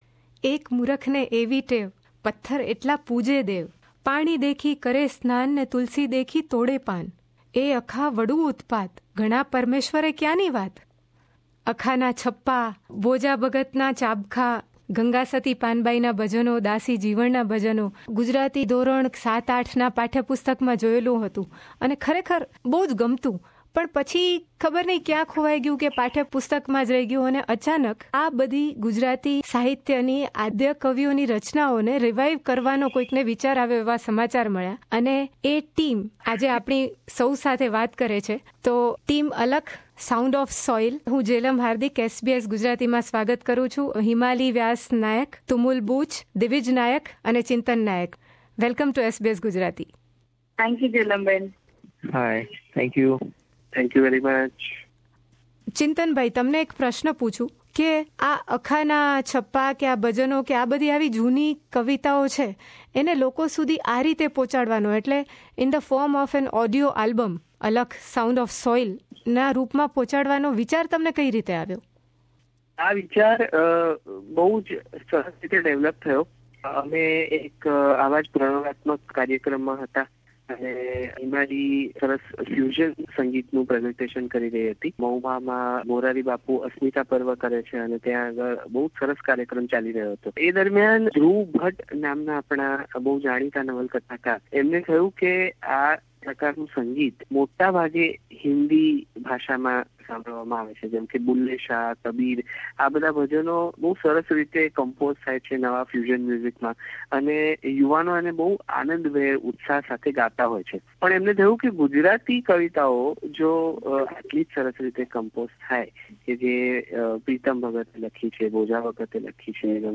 This audio album is created to revive the poetry of our primeval poets like Akho, Bhoja Bhagat, Preetam and Gangasati. In this conversation, the team shares their journey from the concept to the release of this new concept album, and we also have some singing.